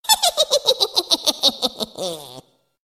Злорадный смех